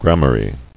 [gram·a·rye]